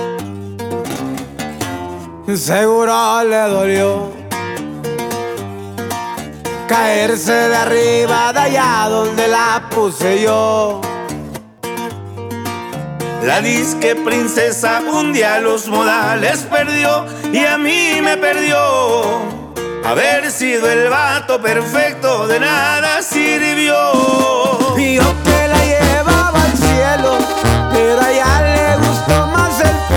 Música Mexicana, Latin